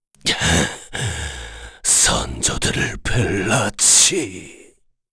Dakaris-Vox_Dead_kr.wav